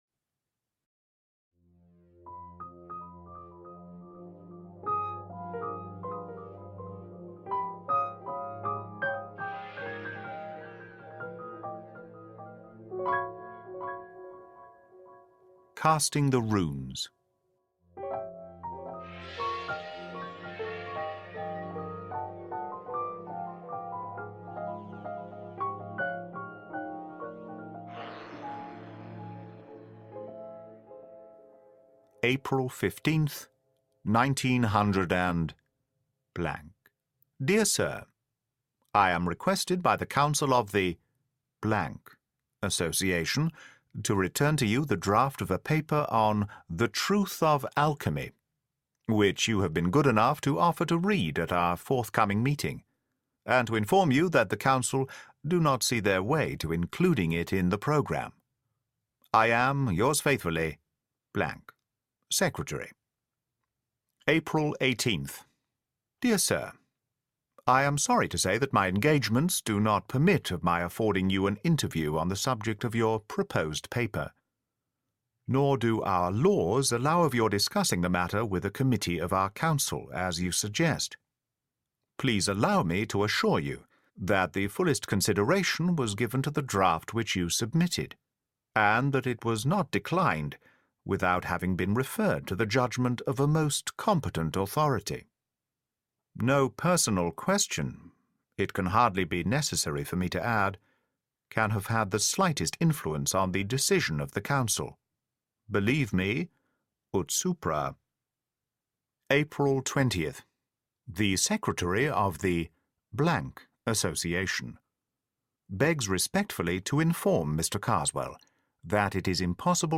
The Empty House: Sherlock Holmes’ Most Famous Return (Audiobook)